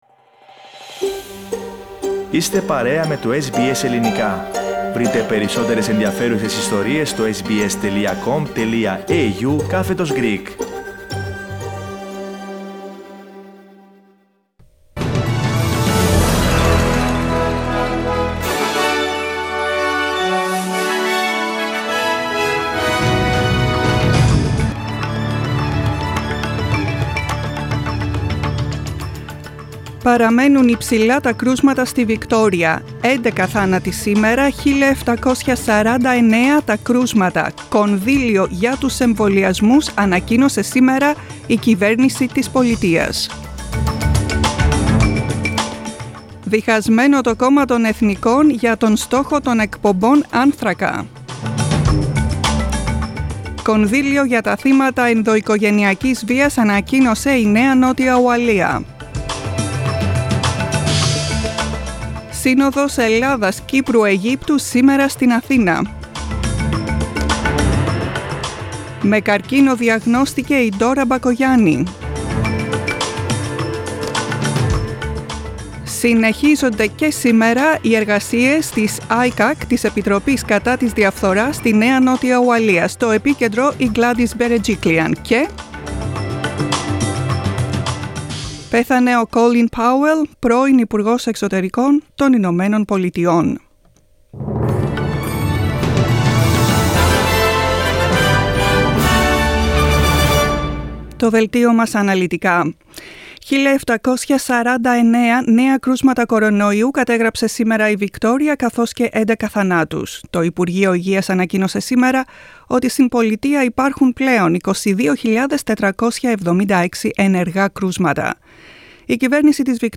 The main bulletin of the day from the Greek Language Program.